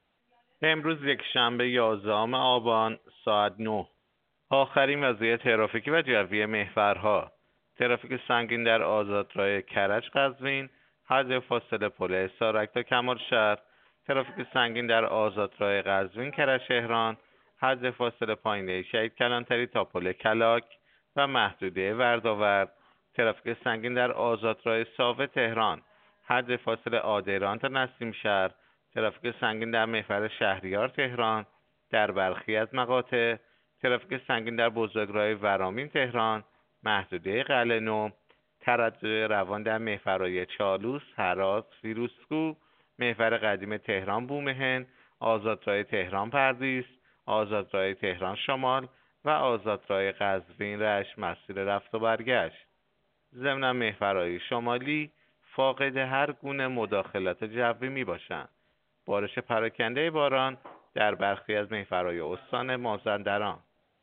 گزارش رادیو اینترنتی از آخرین وضعیت ترافیکی جاده‌ها ساعت ۹ یازدهم آبان؛